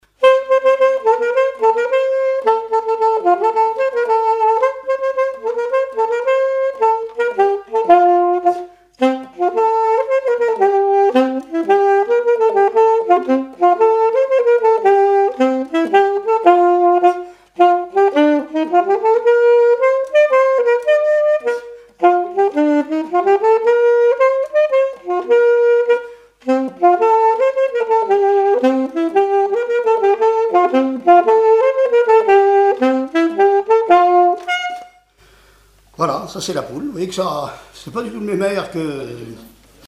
danse : quadrille : poule
témoignages et instrumentaux
Pièce musicale inédite